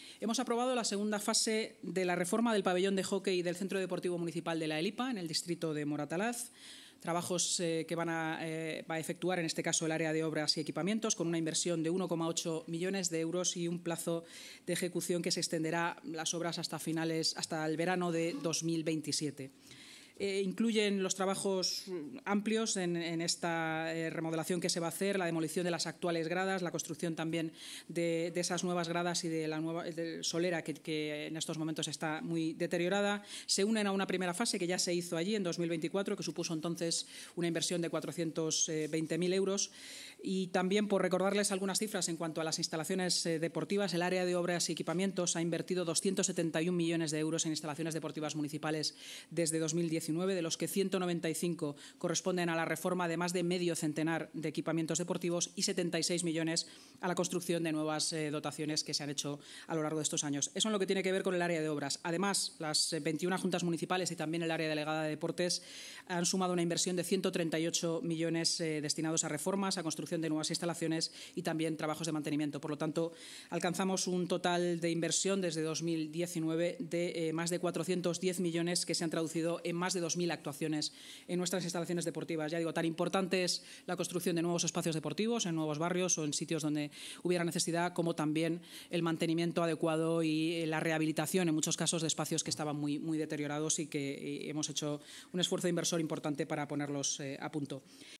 El Ayuntamiento de Madrid ha aprobado la segunda fase de la reforma del pabellón de hockey del Centro Deportivo Municipal La Elipa, en el distrito de Moratalaz. Así lo ha anunciado en rueda de prensa la vicealcaldesa y portavoz municipal, Inma Sanz, tras la reunión semanal de la Junta de Gobierno. Los trabajos, que serán ejecutados por el Área de Obras y Equipamientos, supondrán una inversión de 1,8 millones de euros y se extenderán hasta el verano de 2027.